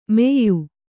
♪ meyyu